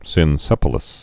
(sĭn-sĕpə-ləs)